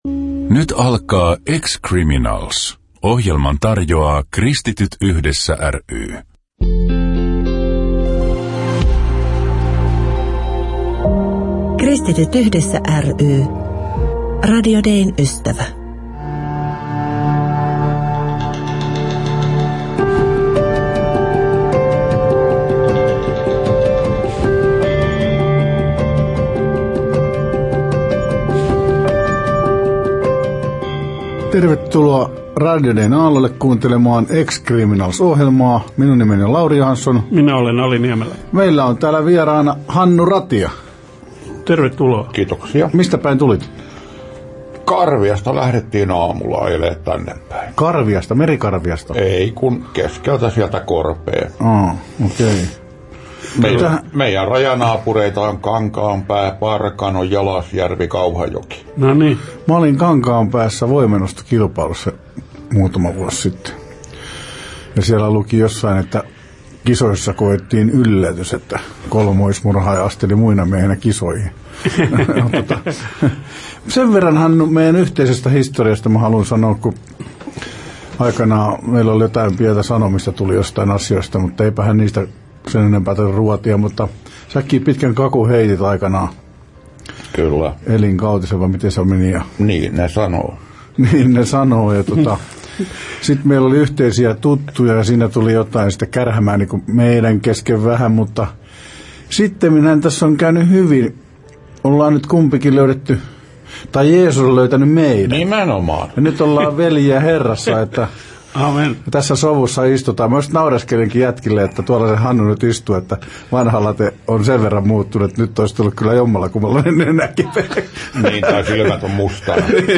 ohjelmassa 7.7.2020. Kuuntele haastattelu: OSA I: